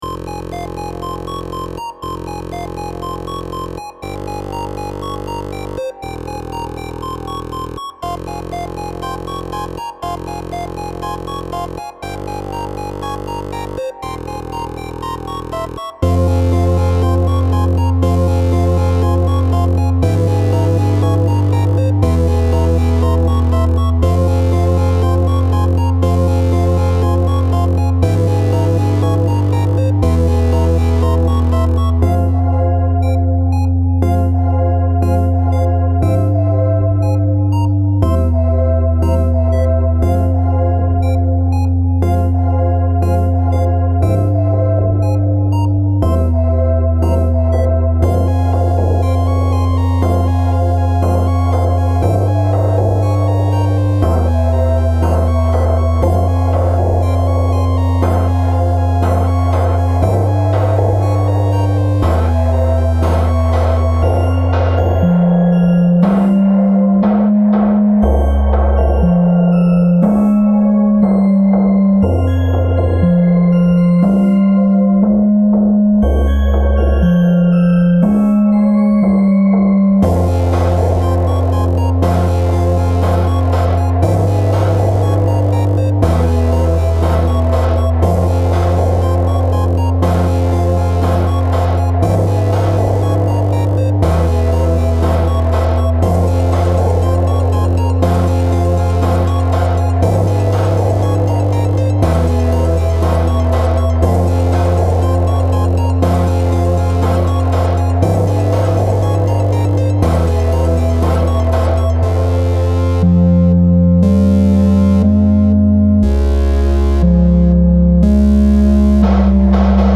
A spacey chiptune song with a threatening theme.
Style Style Ambient, Chiptune
Mood Mood Epic, Intense
Featured Featured Synth
BPM BPM 120